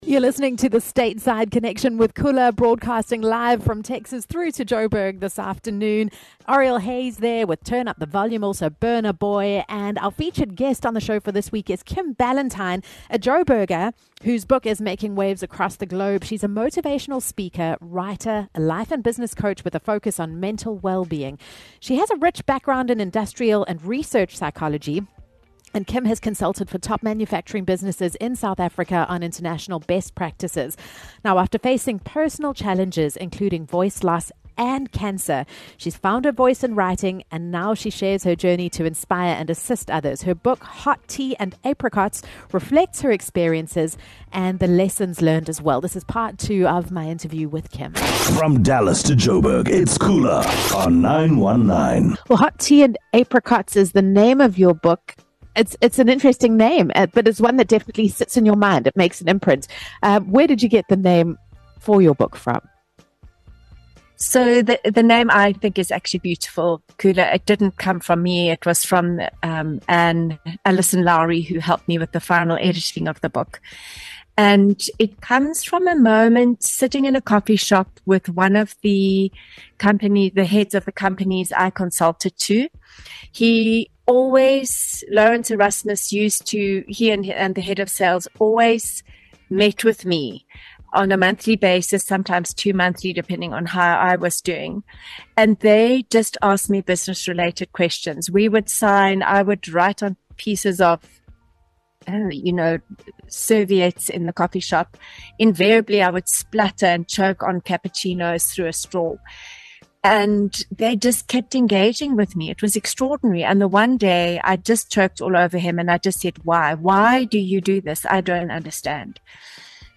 Hear my interview with this incredible woman.